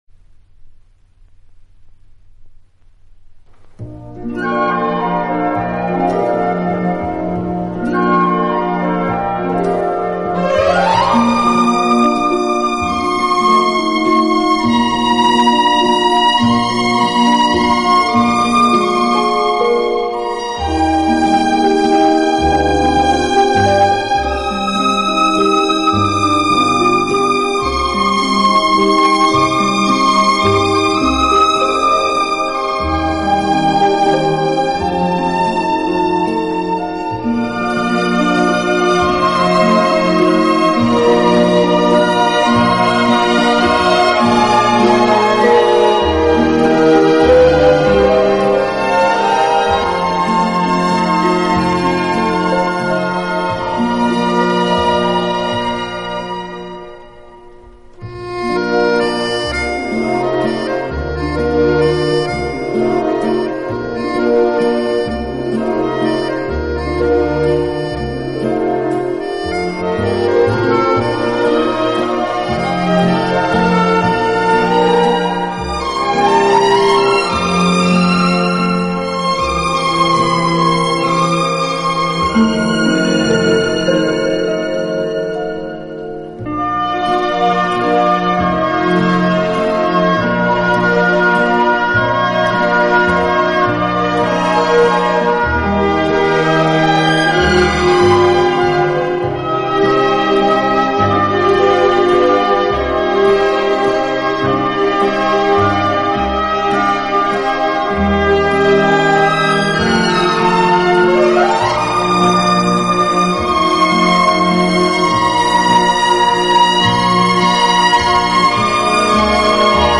【顶级轻音乐】
好处的管乐组合，给人以美不胜收之感。